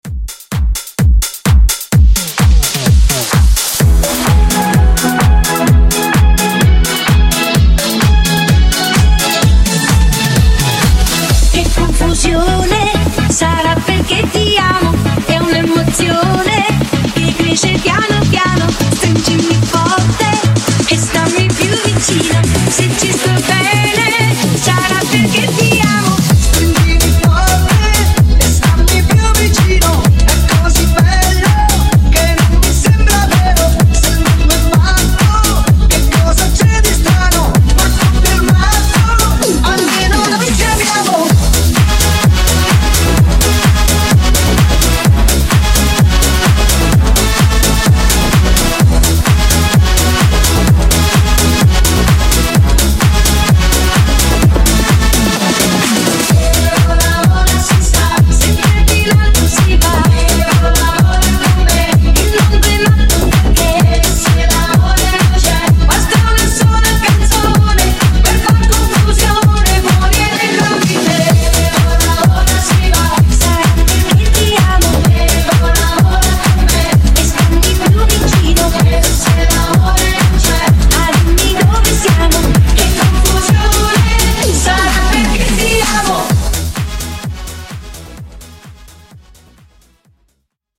Genres: EDM , GERMAN MUSIC , RE-DRUM
Dirty BPM: 173 Time